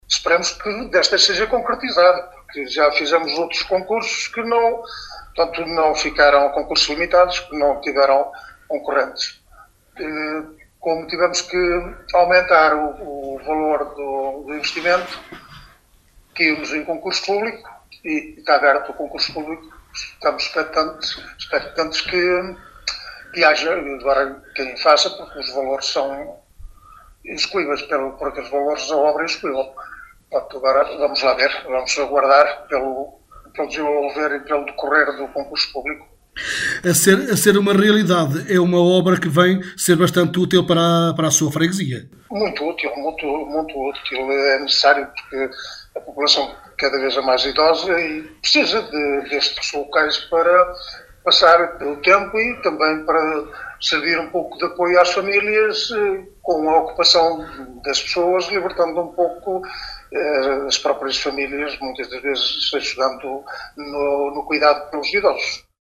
Joaquim Polónio, Presidente da Junta de Freguesia de Côta, em declarações à Alive FM, diz que este Centro de Convívio irá ser muito útil para a população local, em especial a mais idosa.